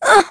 Aselica-Vox_Damage_01.wav